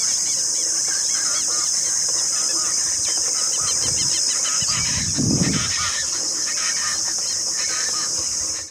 Chaco Chachalaca (Ortalis canicollis)
Country: Argentina
Condition: Wild
Certainty: Recorded vocal
charata.mp3